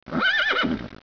Il hennit !!
horse.wav